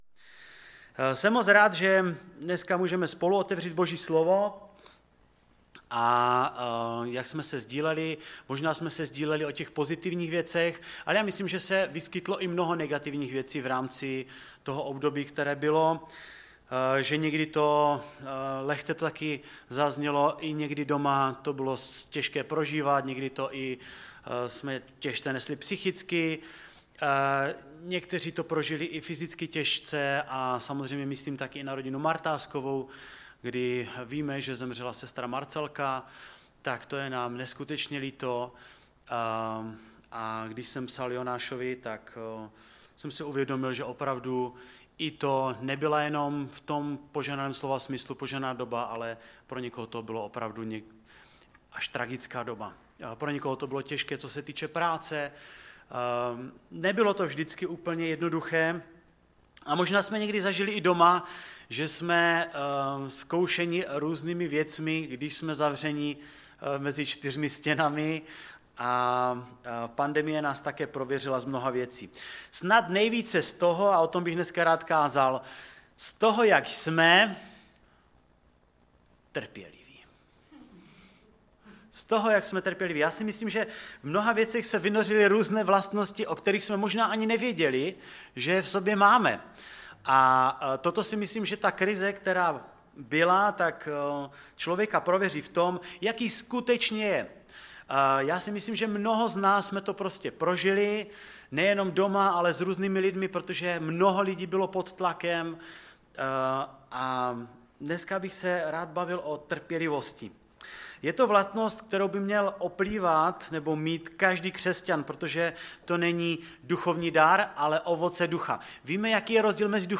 ve sboře Ostrava-Radvanice.
Typ Služby: Kázání